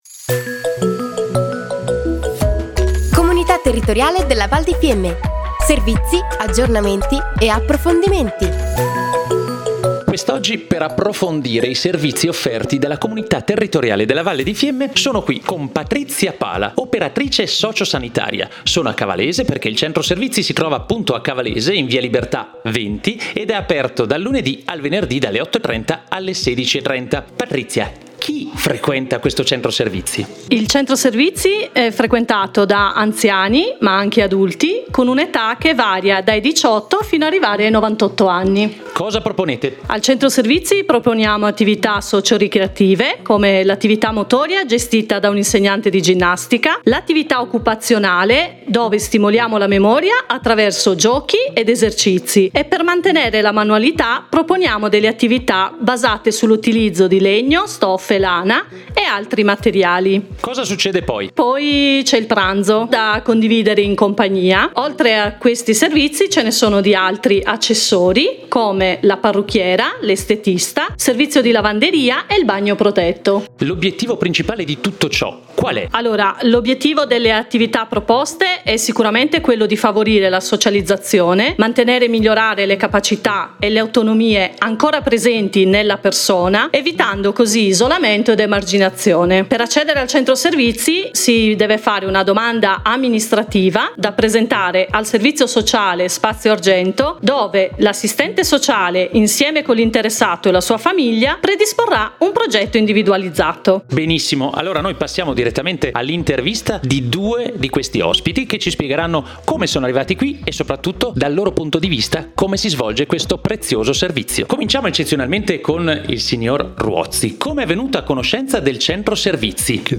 Puntata_9_Spazio_Argento_intervista_utenti.mp3